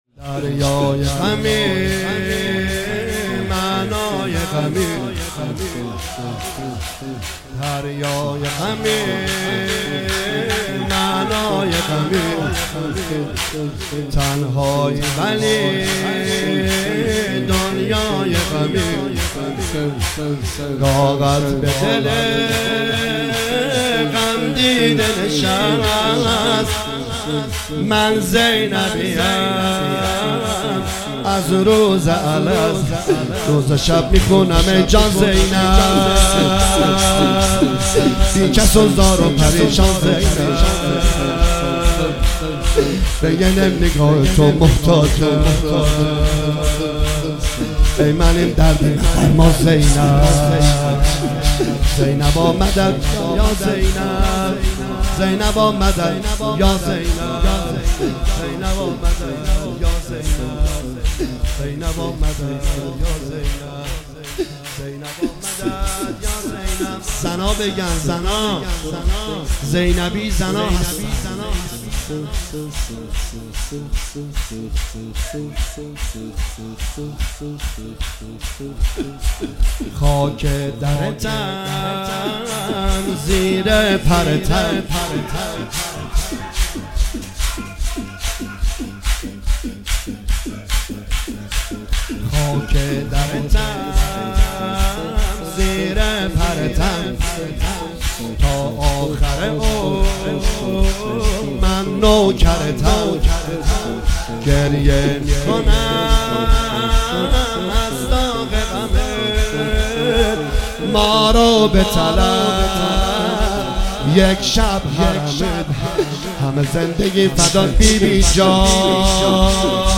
شب شهادت حضرت زینب(س)